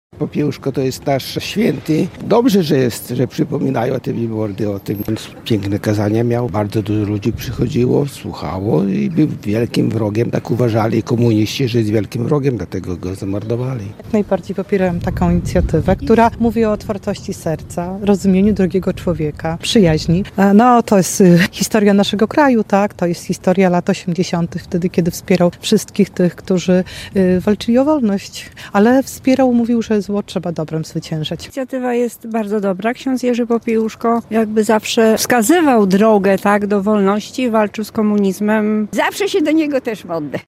Często się do Niego modlę – mówili Radiu Nadzieja mieszkańcy Łomży.